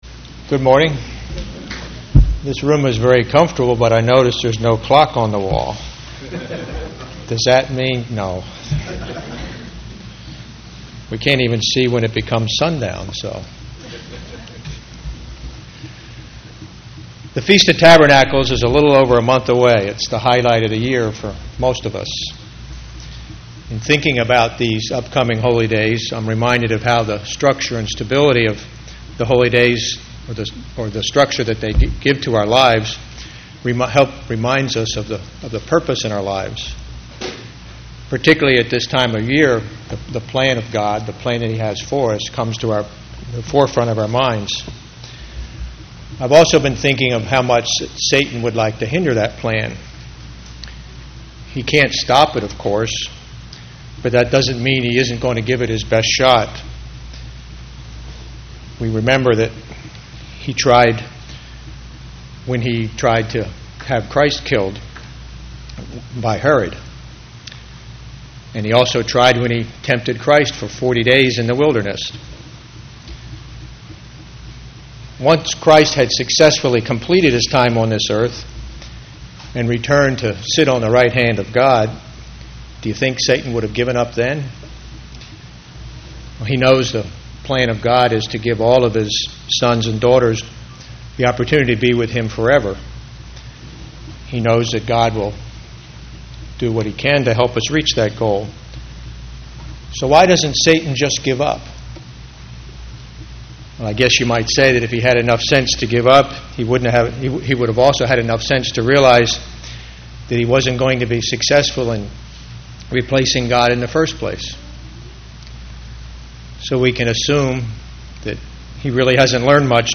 Split Sermon for 8/21 - Some of the deceitful methods used by Satan who will do all he can to keep us from entering the Kingdom of God.
Given in St. Petersburg, FL